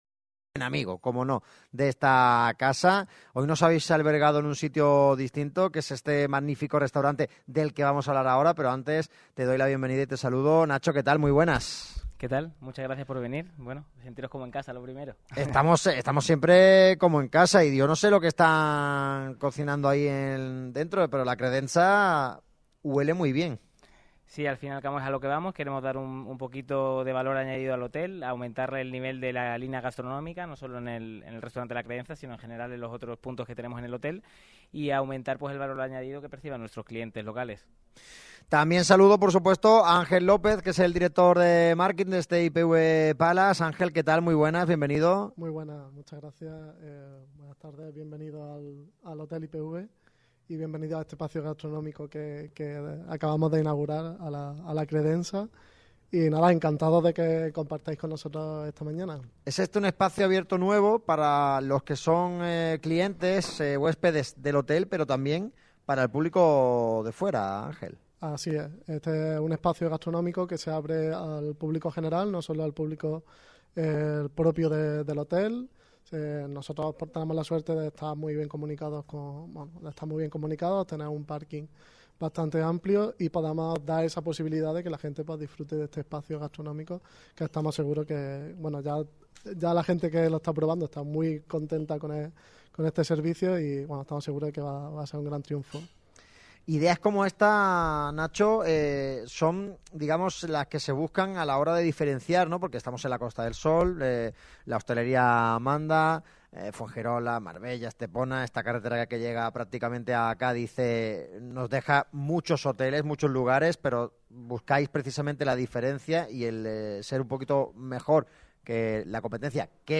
Radio MARCA Málaga desembarca en el Hotel IPV Palace & Spa
Entrevista